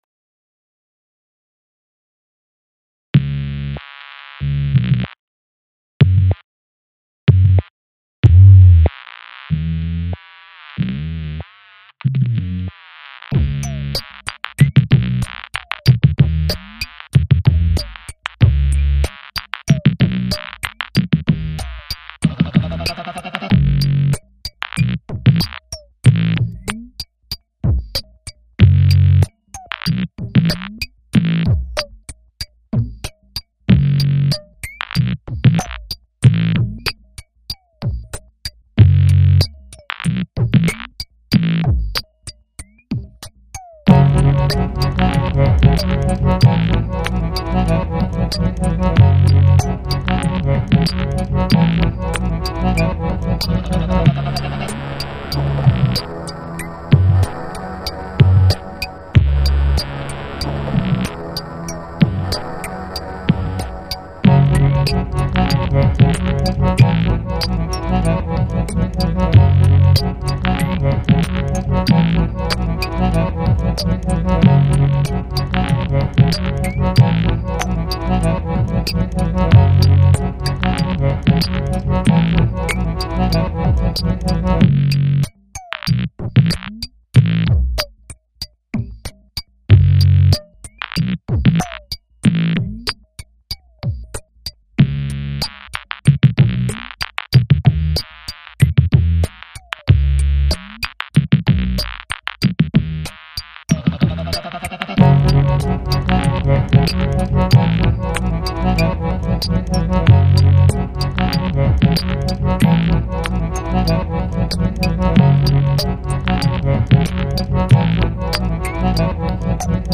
Bon ben c'est pas fini du tout, ca manque de diversité mais voila :
Version instrumentale :
Ben c'est ce que j'avais commencé pour le concours af 2 (samples imposés etc). :mdr: